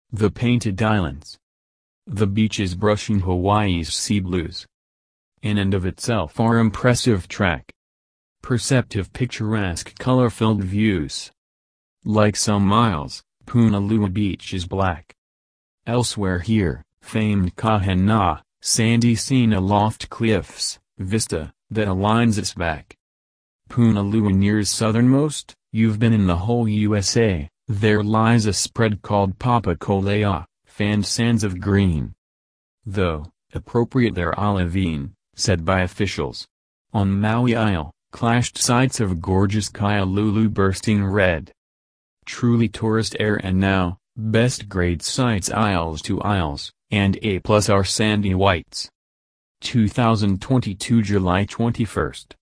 FIVE STANZA TERZA RIMA
*HMS 9/10 - Spelled Papakolea is pronounced with an emphasis on ending "a" (Papakole'a).